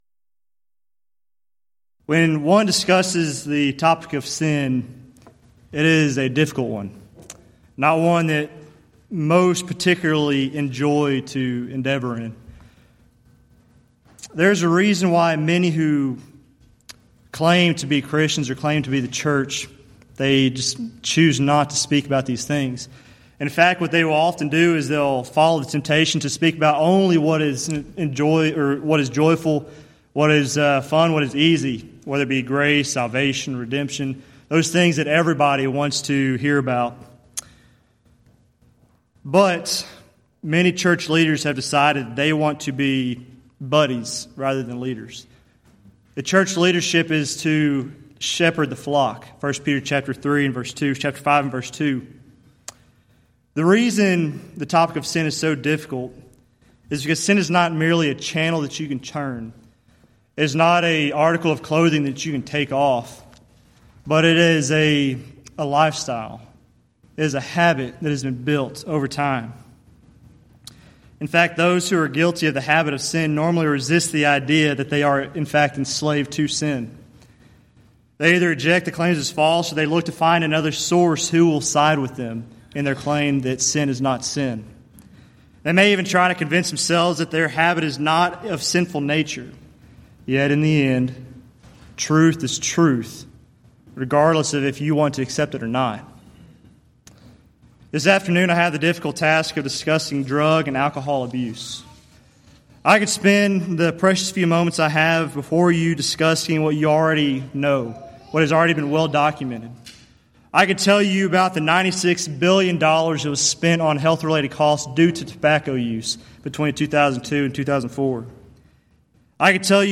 Series: Back to the Bible Lectures Event: 5th Annual Back to the Bible Lectures Theme/Title: Moral Issues Facing Our Nation and the Lord's Church